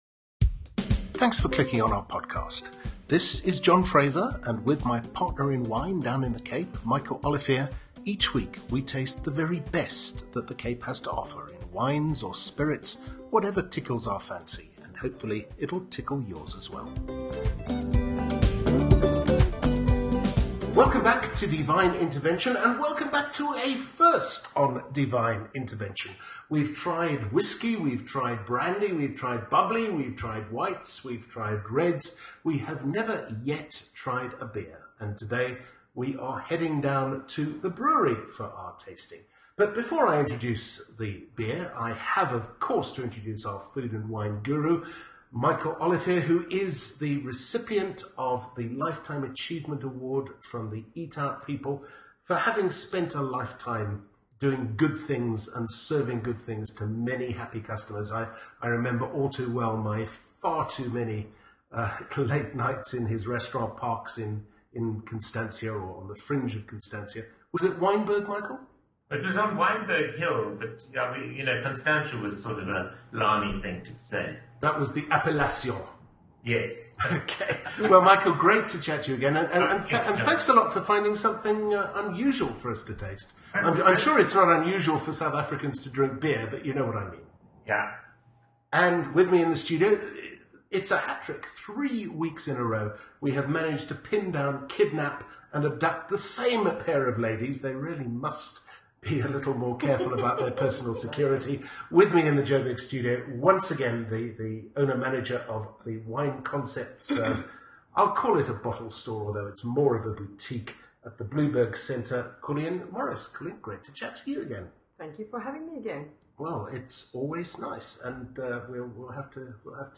in the Johannesburg Studio